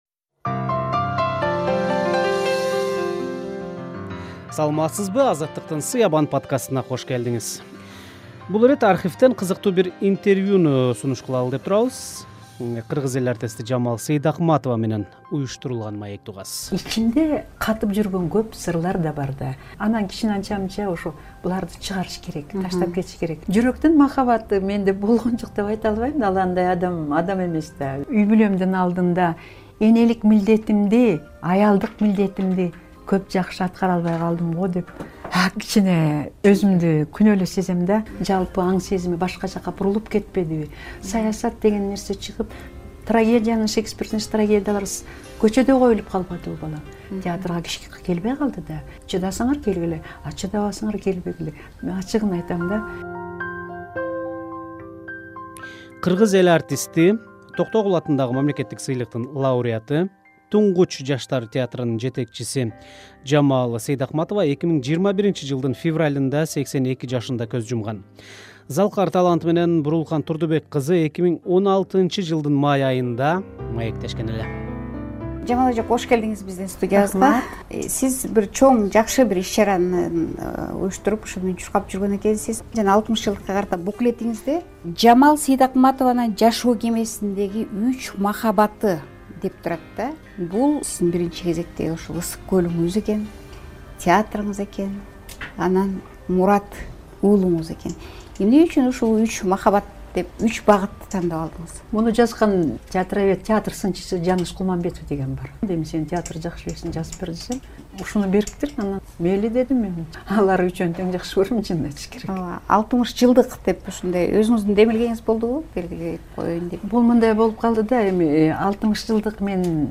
"Сыябан" подкастында "Азаттыктын" архвинен Кыргыз эл артисти Жамал Сейдакматова менен уюштурулган маекти сунуш кылабыз. Токтогул атындагы мамлекеттик сыйлыктын лауреаты, «Тунгуч» жаштар театрынын жетекчиси Жамал Сейдакматова 2021-жылдын февралында 82 жашында көз жумган.